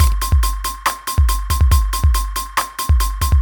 beat beats drumkit fast Gabber hardcore House Jungle sound effect free sound royalty free Music